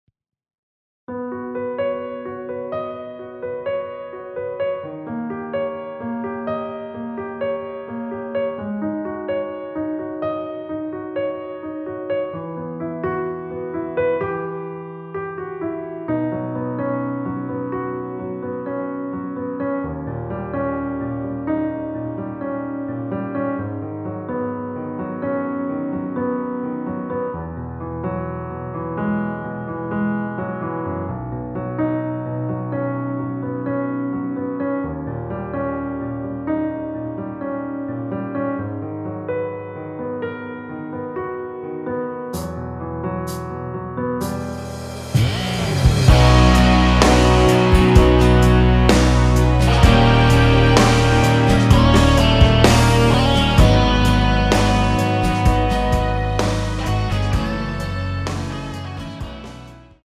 원키에서(-3)내린 (1절앞+후렴)으로 진행되게 편곡한 MR입니다.
앞부분30초, 뒷부분30초씩 편집해서 올려 드리고 있습니다.
중간에 음이 끈어지고 다시 나오는 이유는